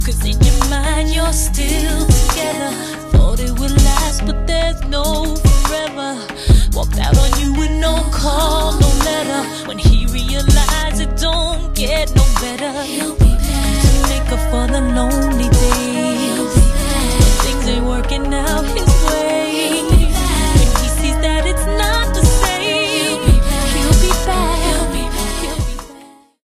This is a sound sample from a commercial recording.
Reduced quality: Yes